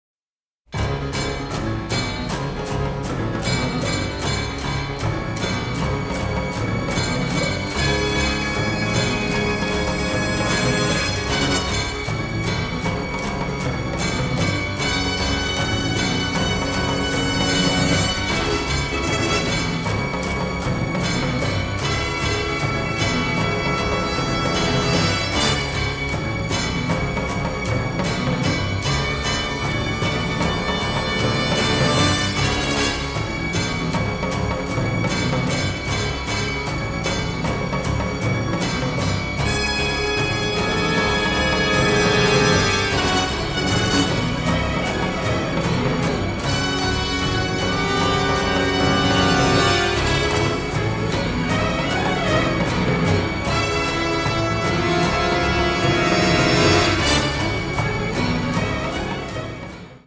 classic war score